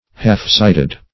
Search Result for " half-sighted" : The Collaborative International Dictionary of English v.0.48: Half-sighted \Half"-sight`ed\ (-s[imac]t`[e^]d), a. Seeing imperfectly; having weak discernment.